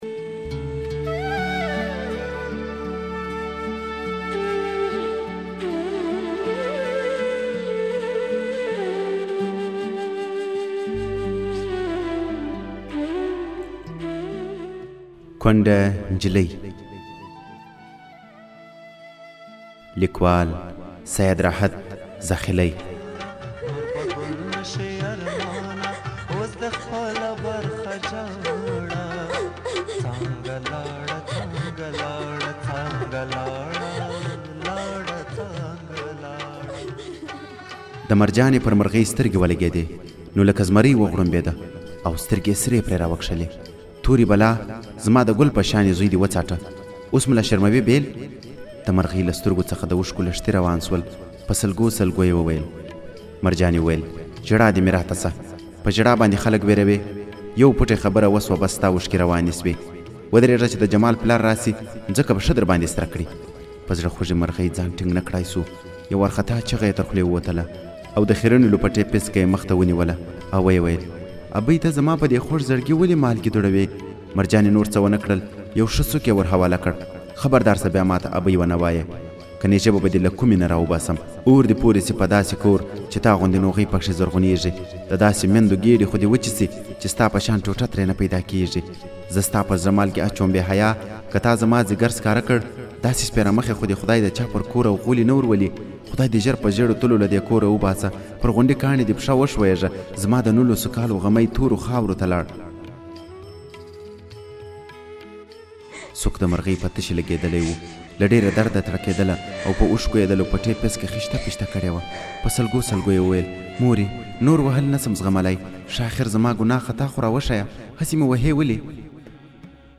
په خپل ږغ کي لوستې